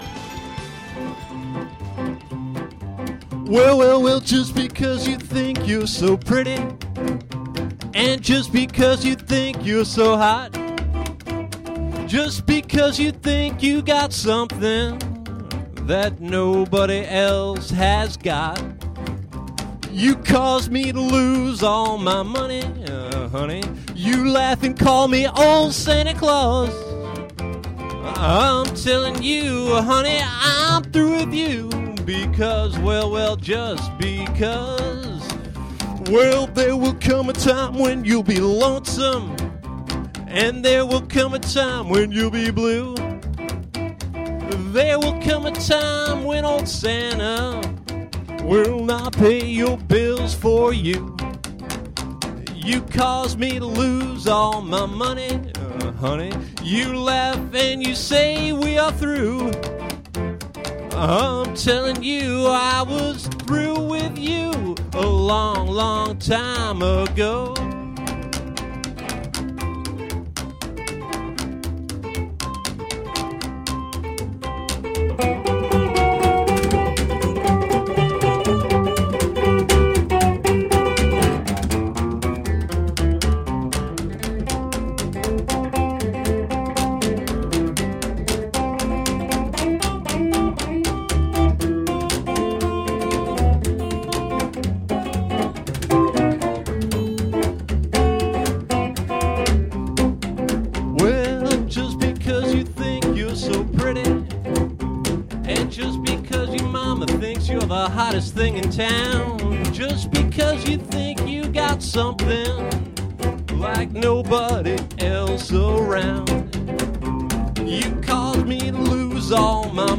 a Rockabilly / Honky Tonk / Garage band
upright bassist